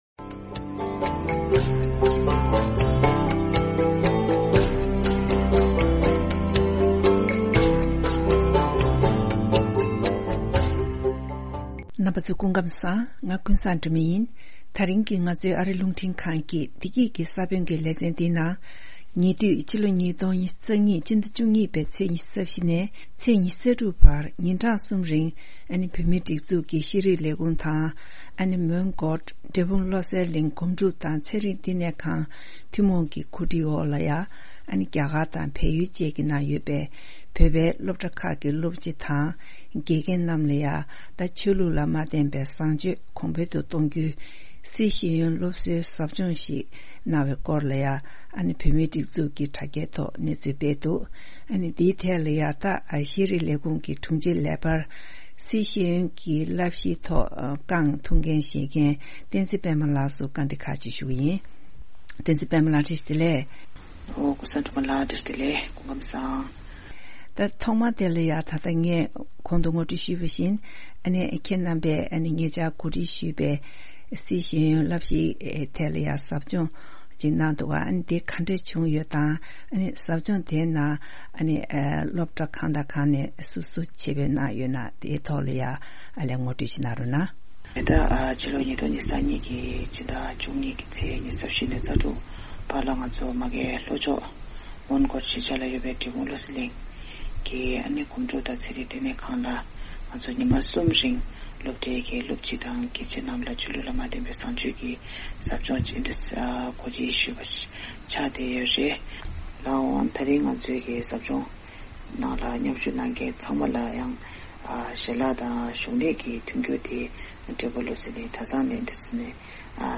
བཅར་འདྲི་ཞུས་ཡོད།